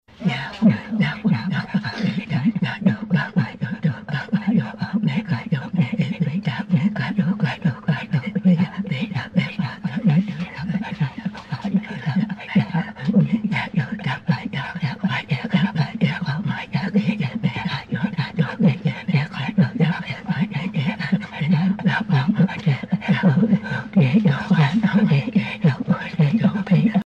Qiarpaa (jeu vocal)
chants des Inuïts